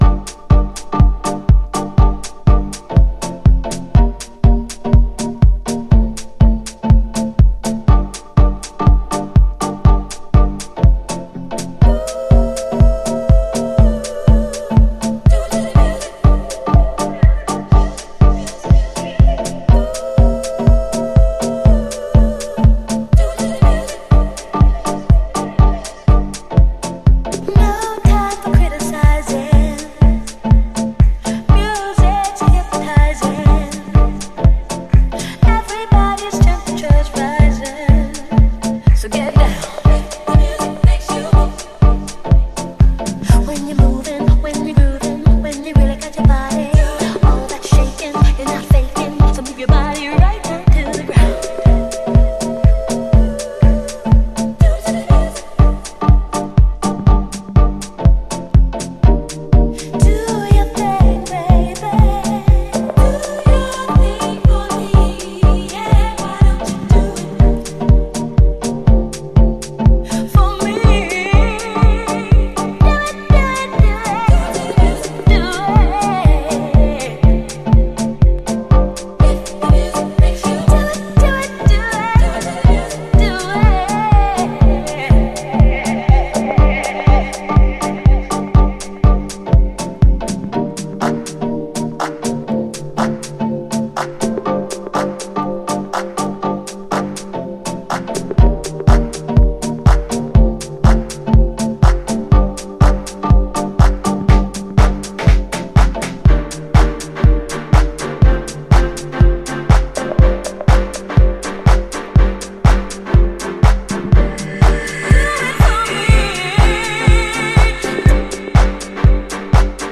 House / Techno
オリジナルを活かしながら、安定したグルーヴとなんともせつない飛翔感が同居したハウスに仕上がっています。